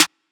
Snr (Lie to Me).wav